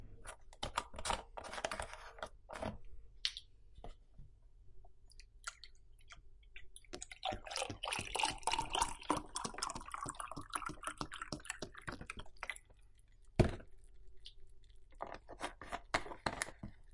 描述：在Adobe试听中使用ZoomH4Edited进行录制，清洁背景声音并提高音量。当我将牛奶倒入杯中时，录音机放在桌面上。
标签： 牛奶 奶瓶 玻璃 倒水 杯子 液体 集装箱 饱满 特写 前景 声音
声道立体声